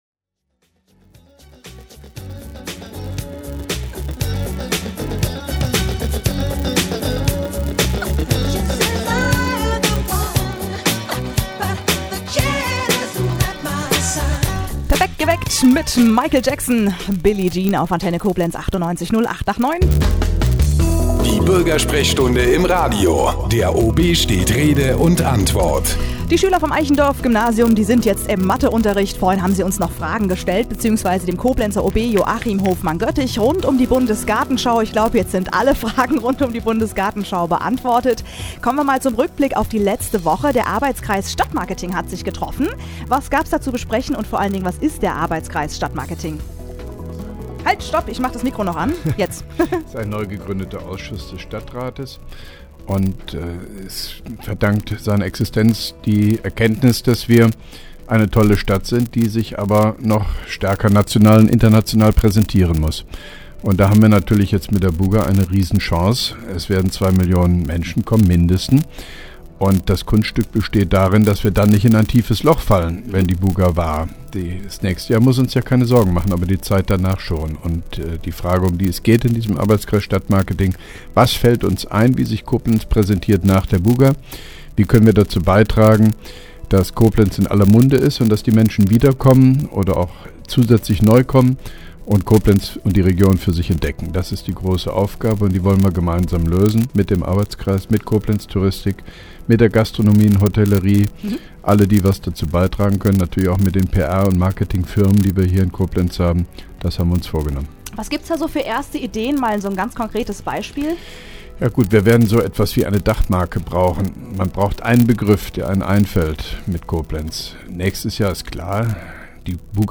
(5) Koblenzer OB Radio-Bürgersprechstunde 07.09.2010